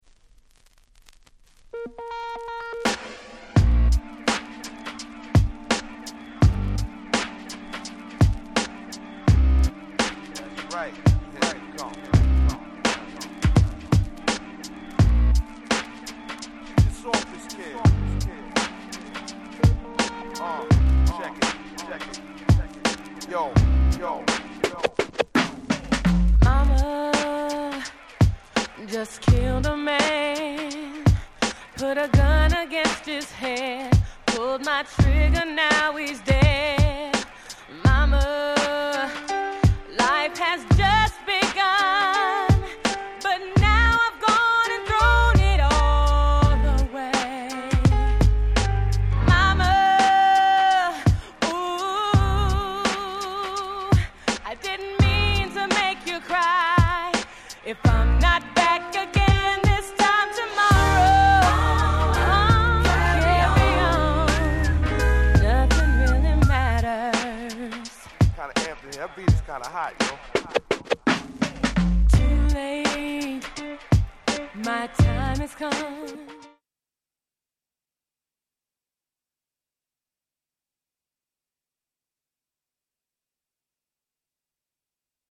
90's R&B Classic !!
意外と歌もウマく